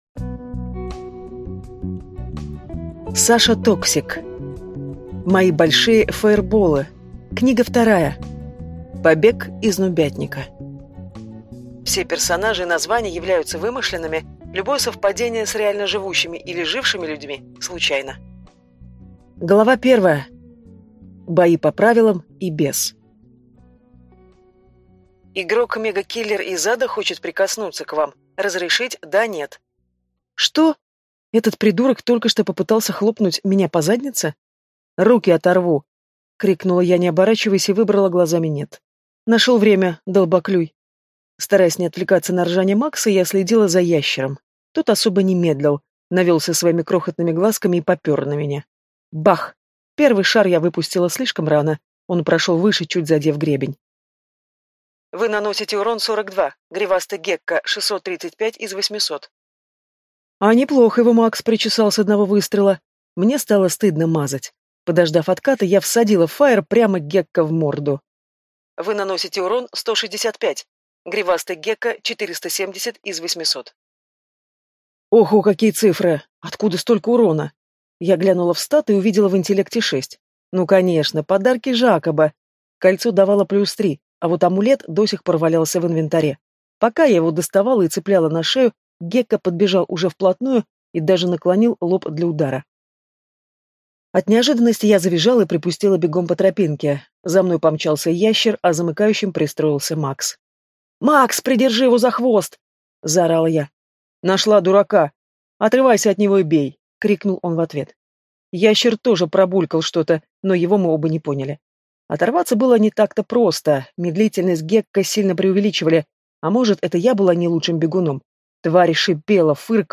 Аудиокнига Мои большие файерболы. Книга 2. Побег из нубятника | Библиотека аудиокниг